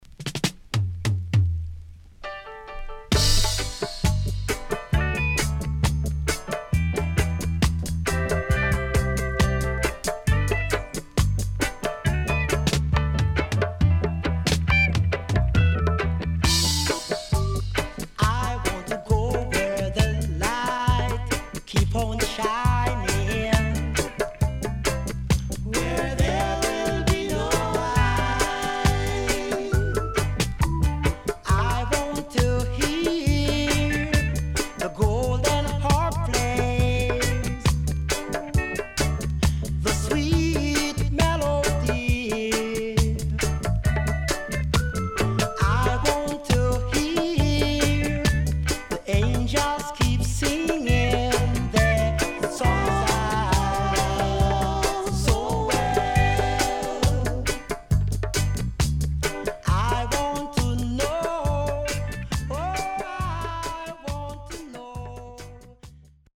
Rare.Good Vocal & Dubwise